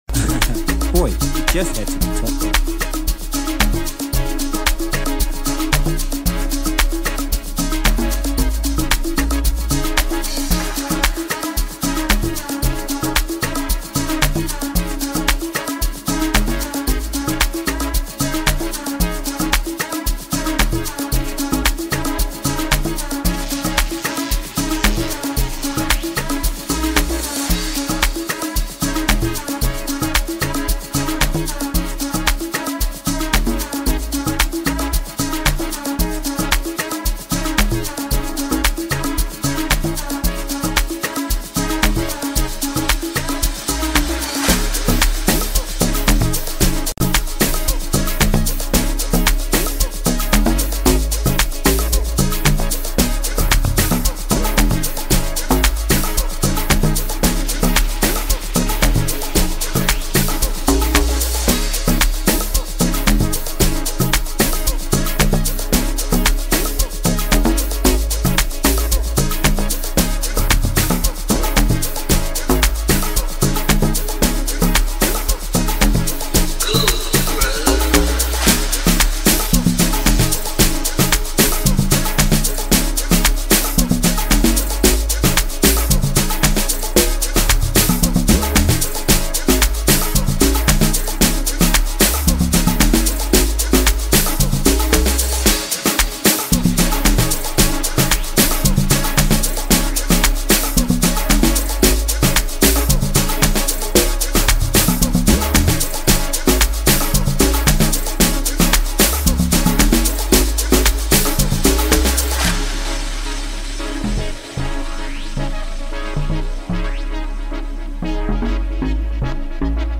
Song Genre: Amapiano.